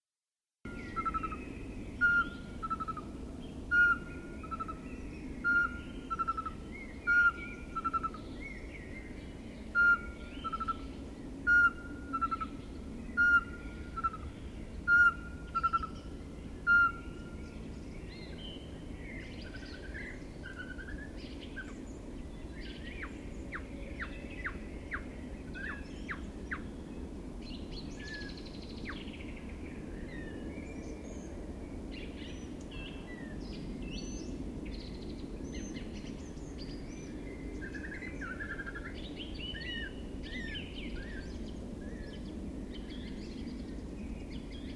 Chant-chouette-chevechette-2.mp3